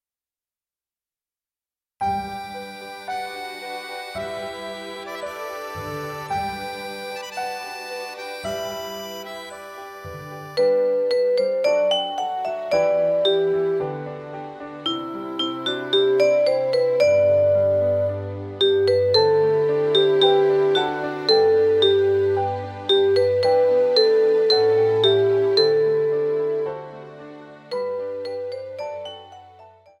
Instrumental Solos Cello